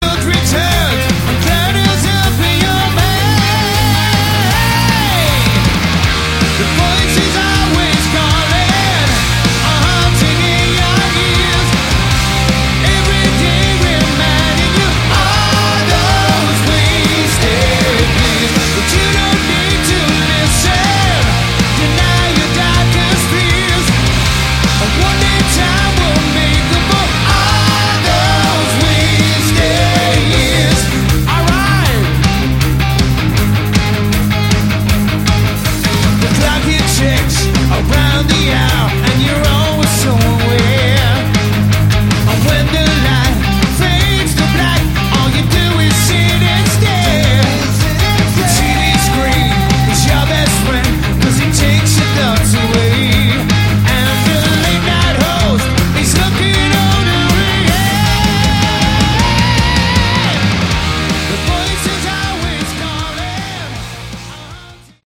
Category: Melodic Rock
Disc 2 is a bonus live album recorded in Ludwigsburg in 2013